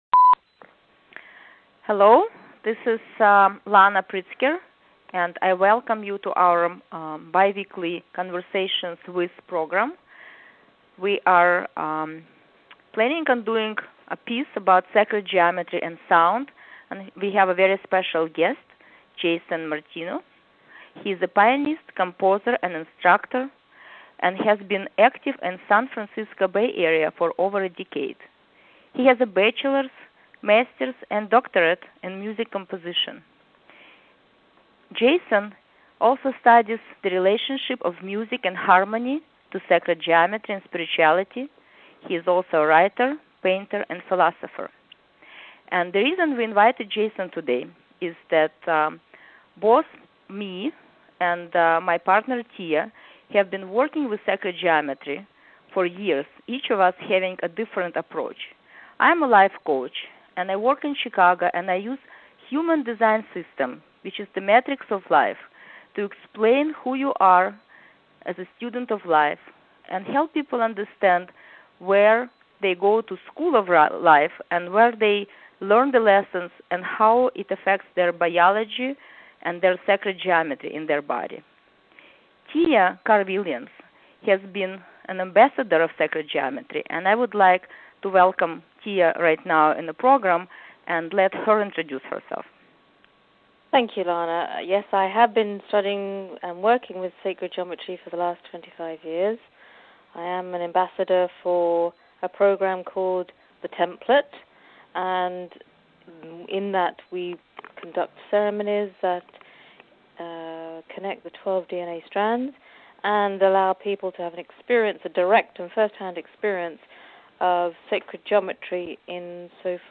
Podcast interview recorded live, February 6th, 2007